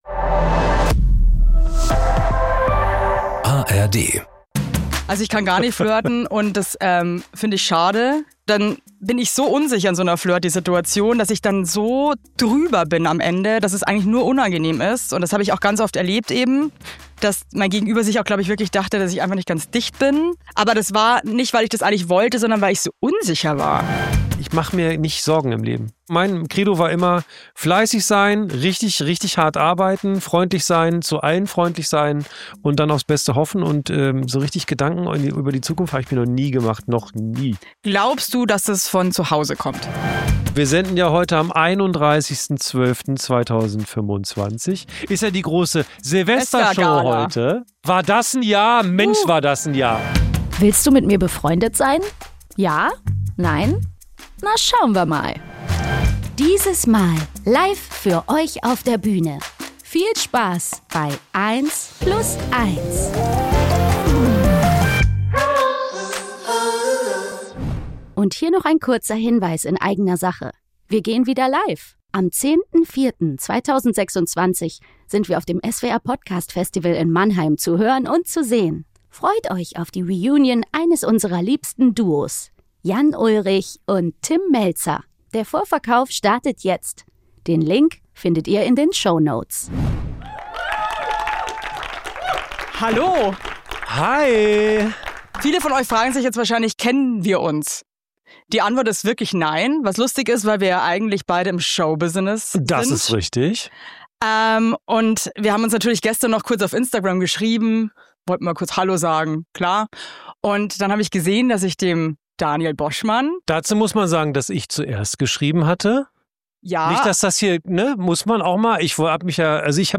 Daniel sollte Gülcan wiedertreffen, diese ist leider krank und zack: Evelyn Weigert springt ein – im November live in Berlin!